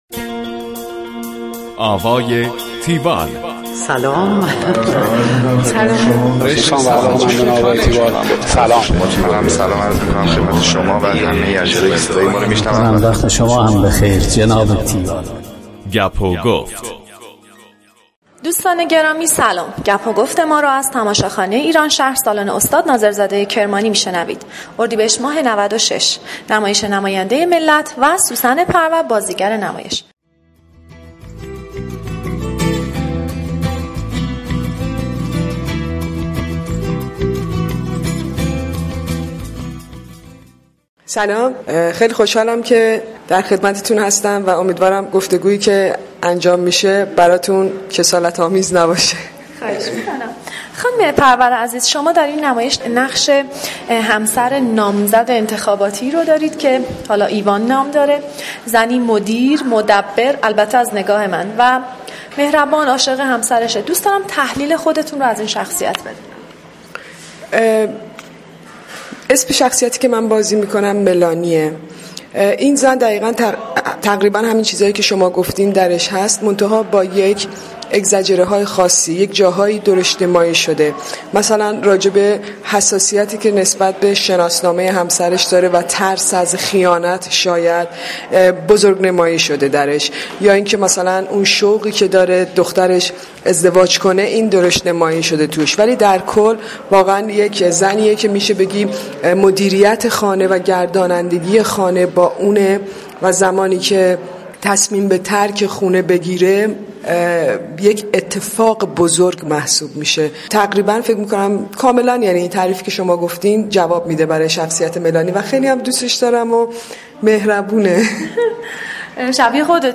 گفتگوی تیوال با سوسن پرور